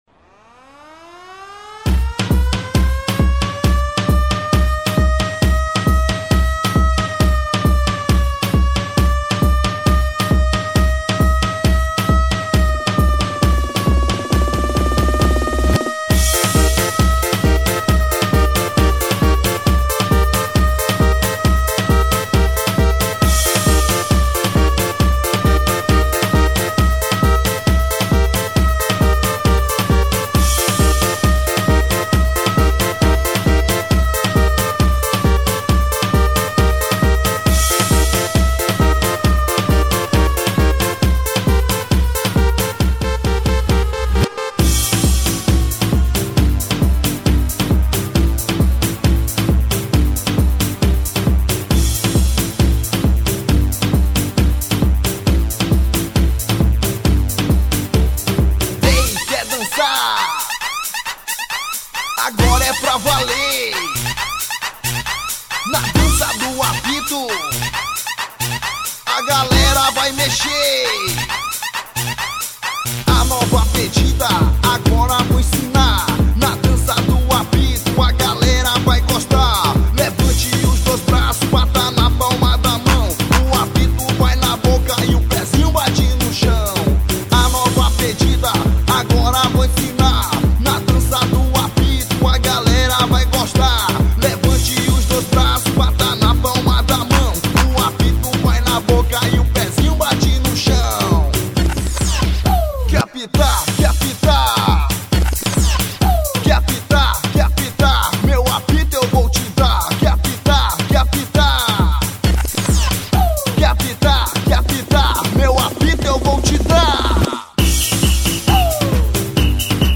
EstiloReggaeton